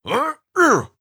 RiftMayhem / Assets / 1-Packs / Audio / NPC or Player / Effort Sounds / 33.
33. Effort Grunt (Male).wav